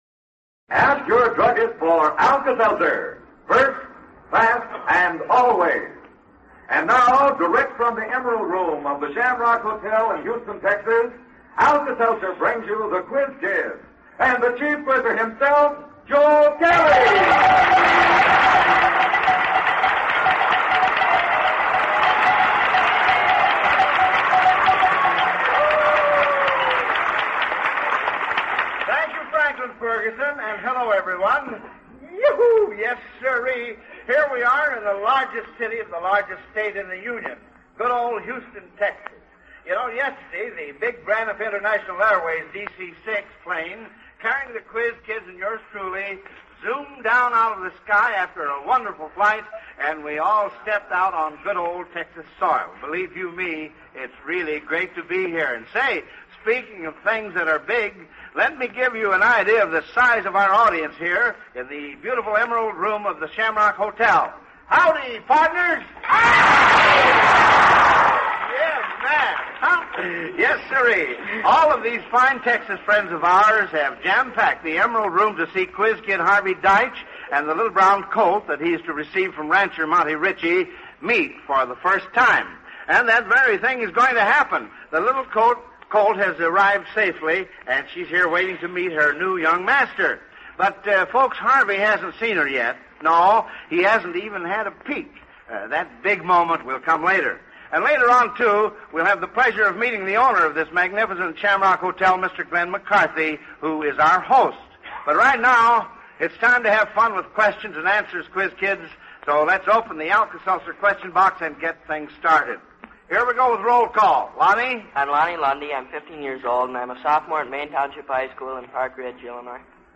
1951-03-25-Live-from-the-Shamrock-Hotel.mp3
Live from the Shamrock Hotel, March 25, 1951